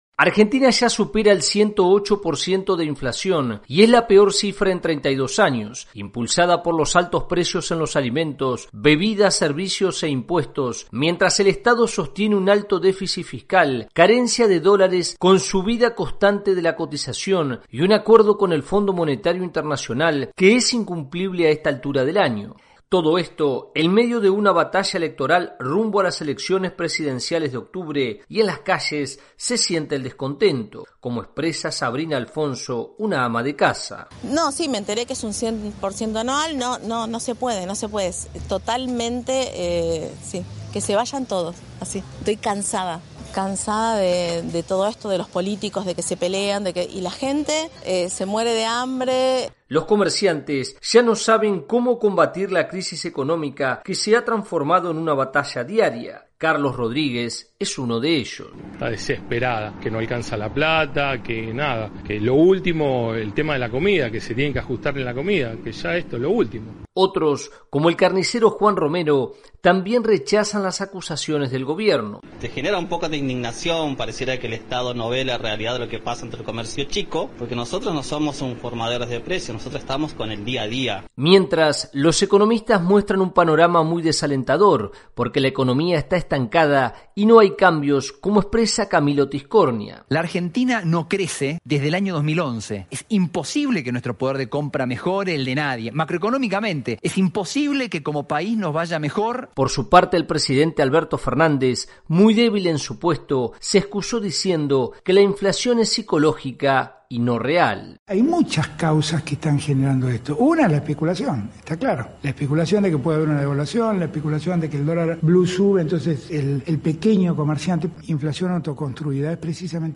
En medio de la campaña electoral por las elecciones presidenciales de octubre, Argentina sufre la peor inflación de las últimas tres décadas. El informe